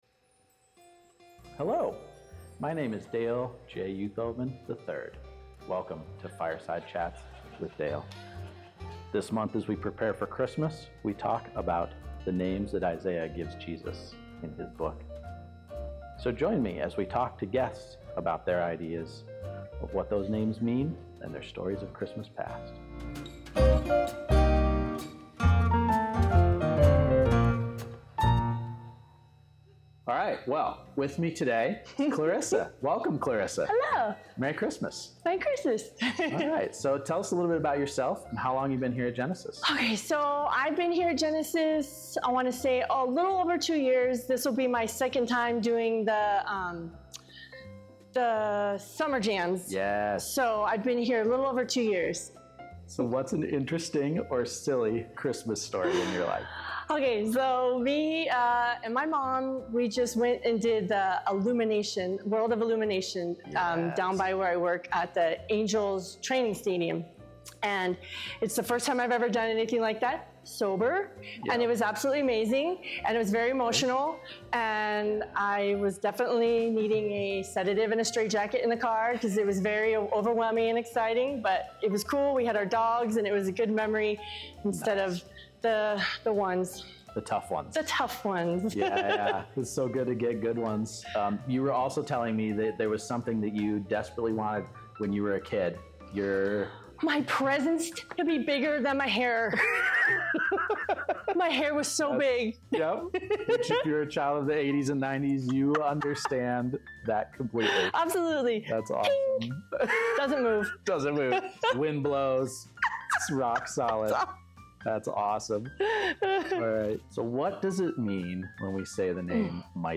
keyboard_arrow_left Sermons / He Shall Be Called Series Download MP3